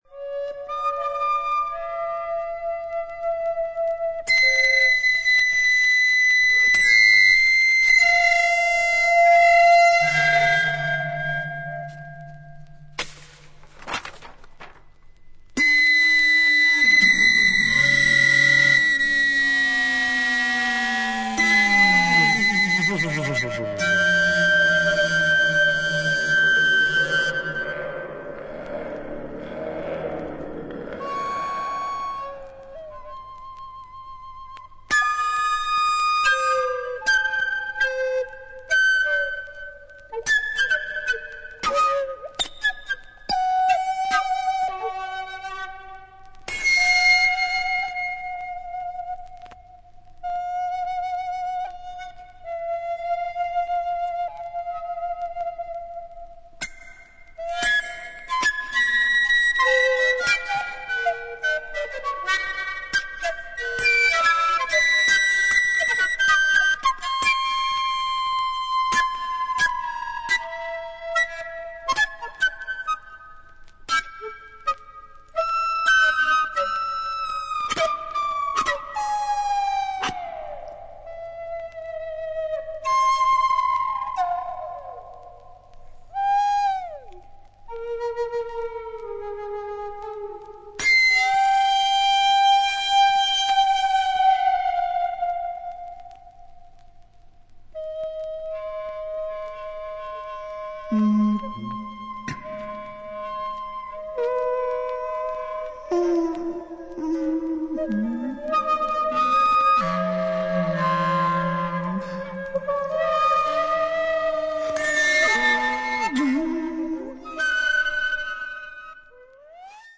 2人のリコーダーの為の作品